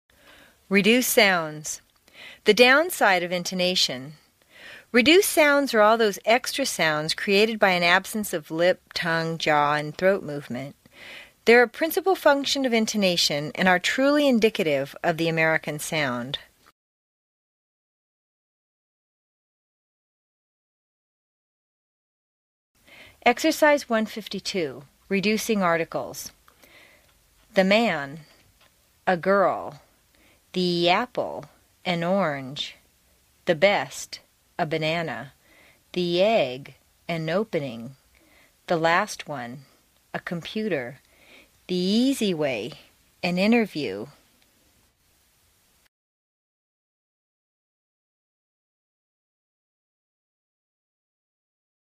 在线英语听力室美式英语正音训练第49期:弱读&练习的听力文件下载,详细解析美式语音语调，讲解美式发音的阶梯性语调训练方法，全方位了解美式发音的技巧与方法，练就一口纯正的美式发音！